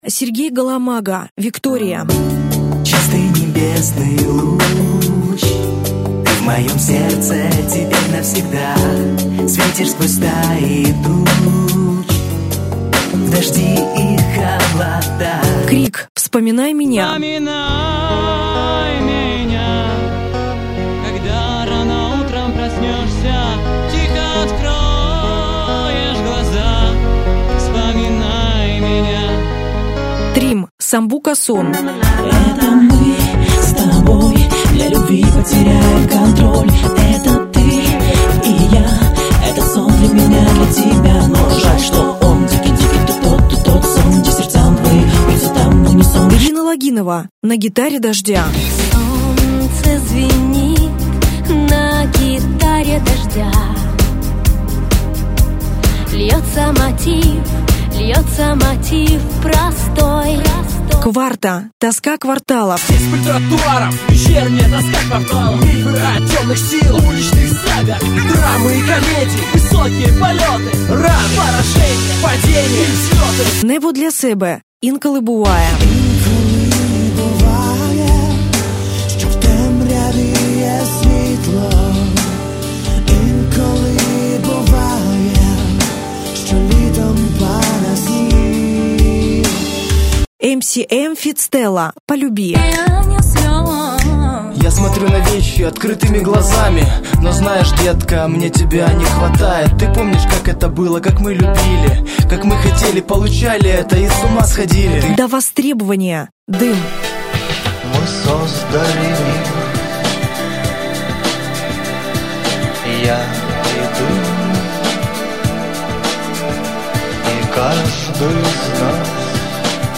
Фрагменти пісень учасників конкурсу